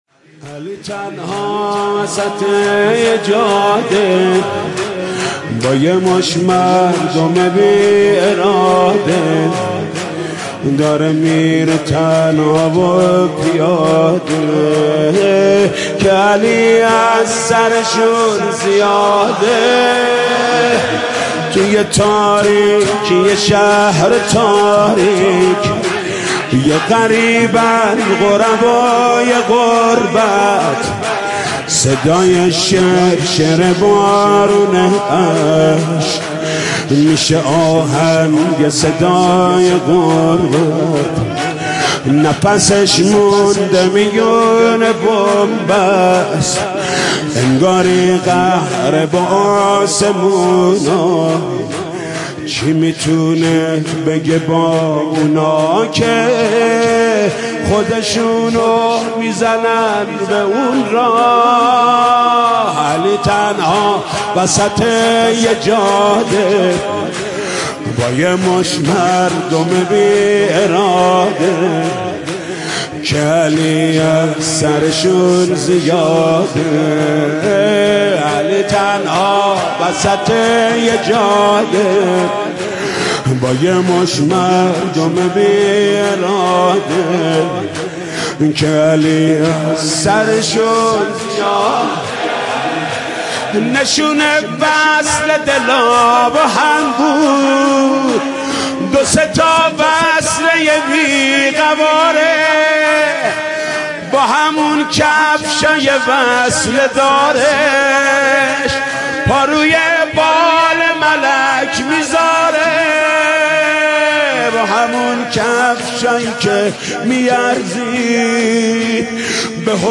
مداحی جدید حاج محمود کریمی شب بیستم رمضان ۹۷
شور